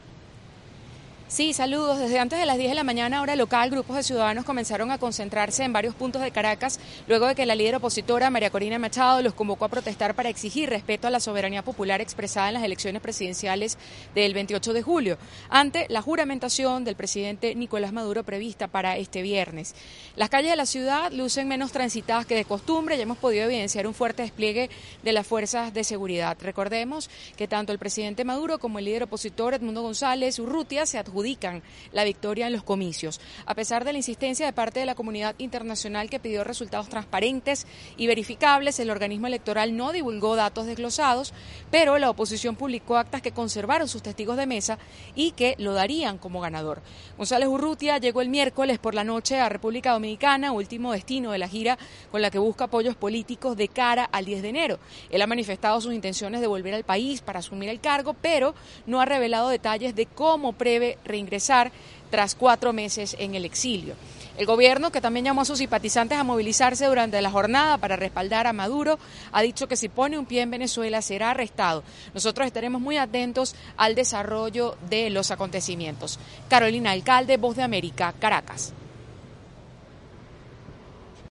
Este es un informe especial